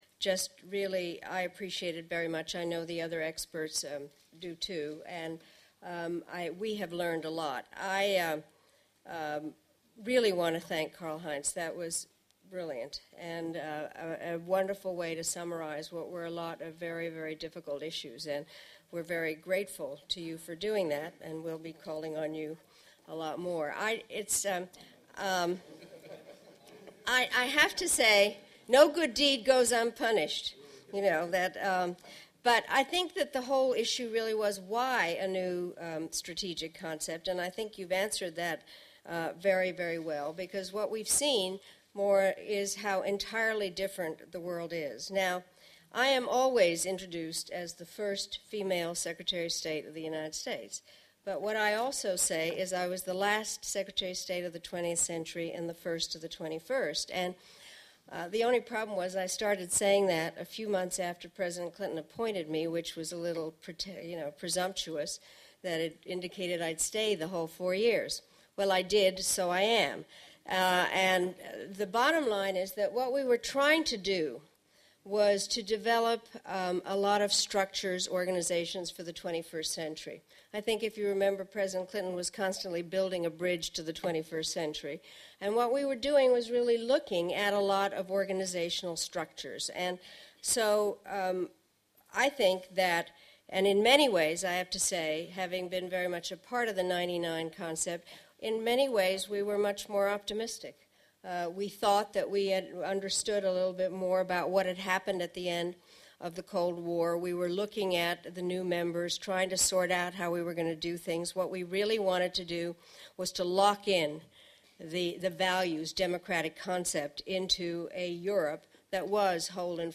NATO’s new Strategic Concept discussed at Luxembourg seminar
•   Concluding remarks: The Hon. Madeleine Albright, Chair of the Group of Experts ENG .